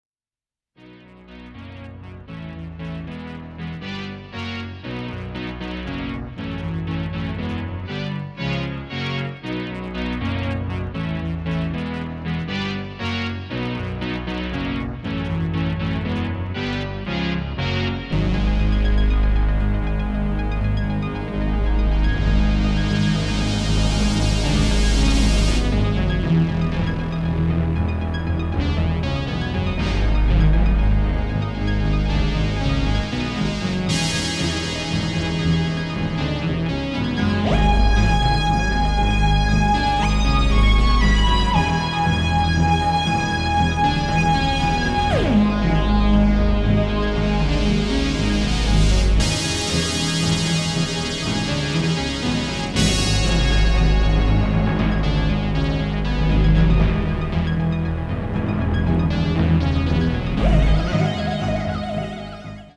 studio in the Los Angeles Hills
progressive rock and New Wave music